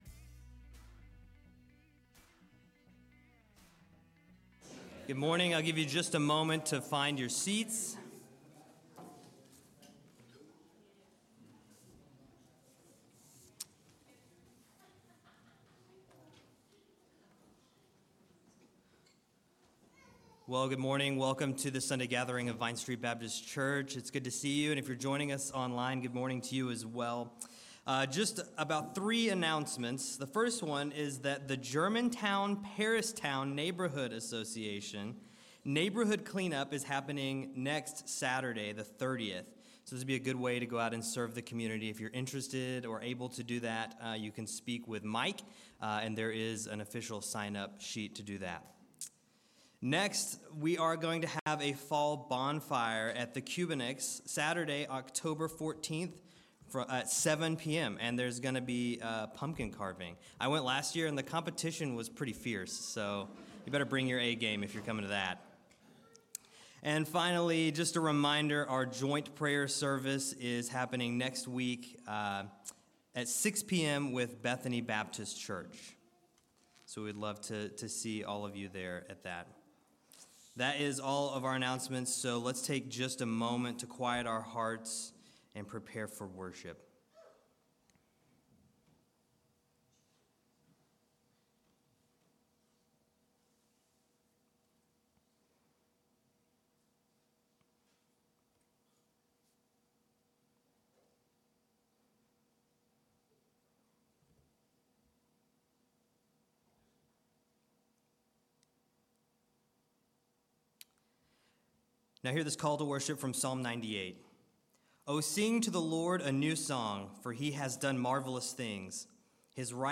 September 24 Worship Audio – Full Service